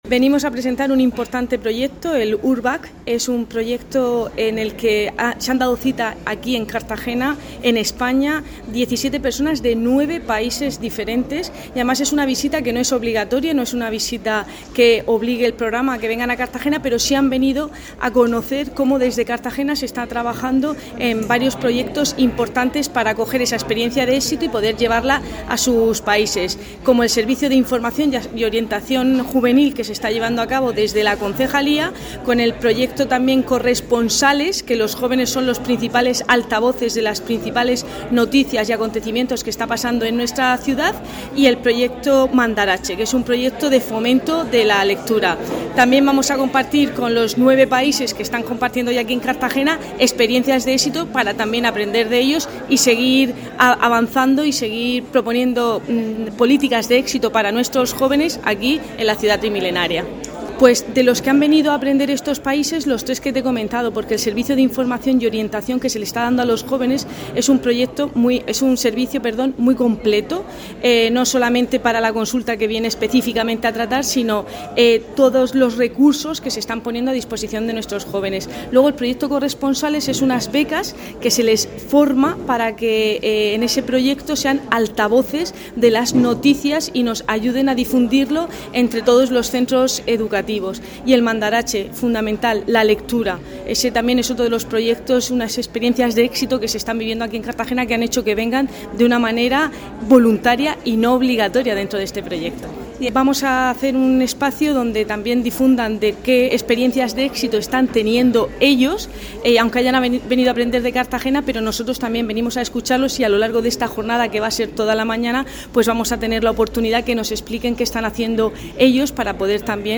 Enlace a Declaraciones de Cristina Mora.